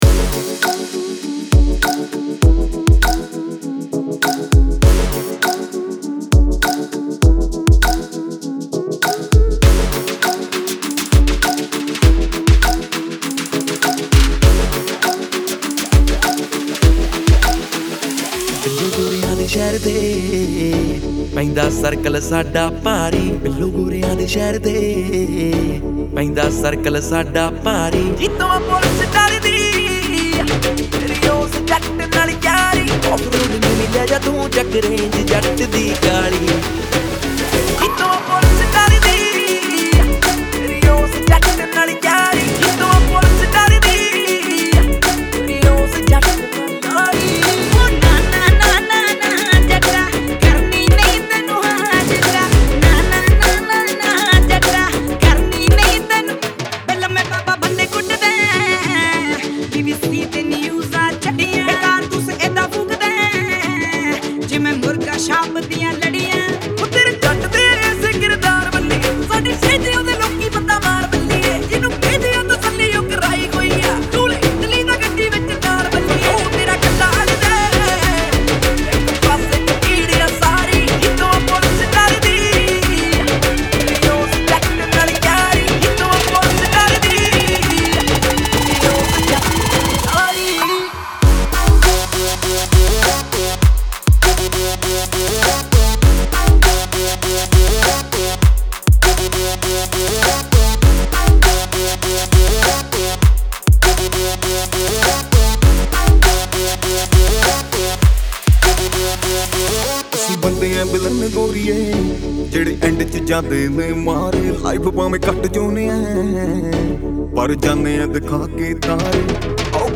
Punjabi DJ Remix Songs